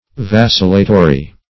Search Result for " vacillatory" : The Collaborative International Dictionary of English v.0.48: Vacillatory \Vac"il*la*to*ry\, a. Inclined to vacillate; wavering; irresolute.